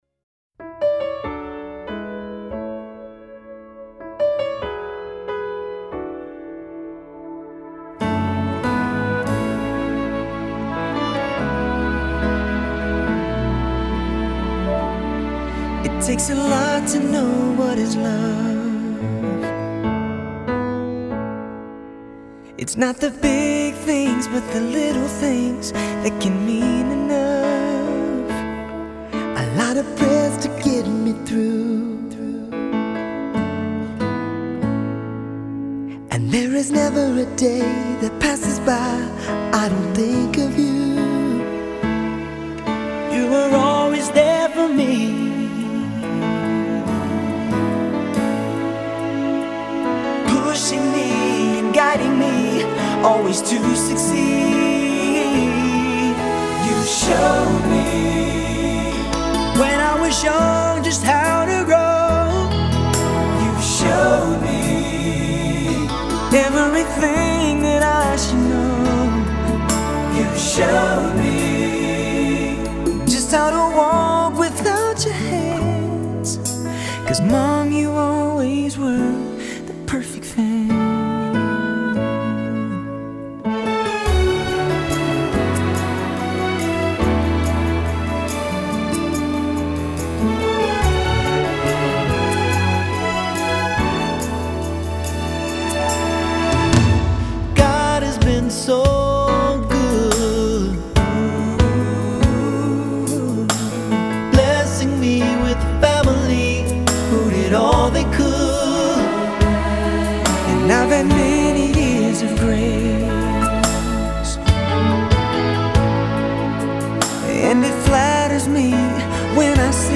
Жанр: Dance Pop